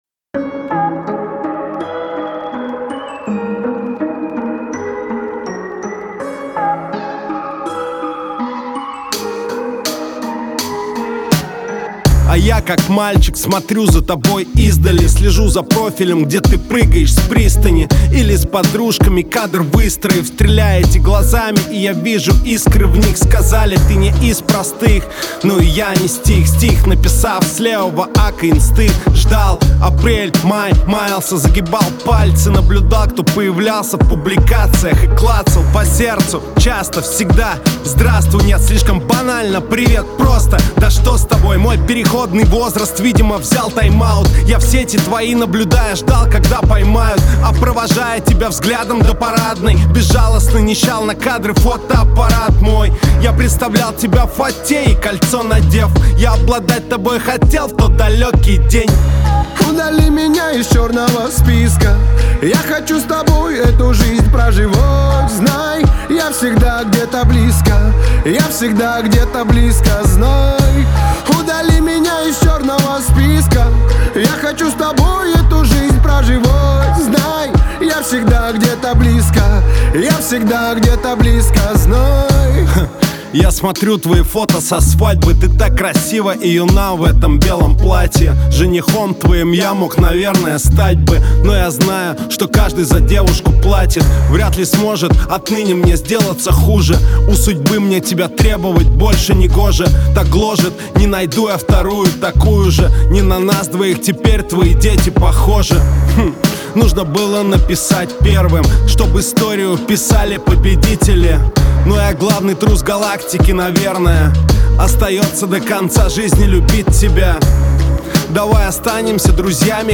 хип-хоп и рэп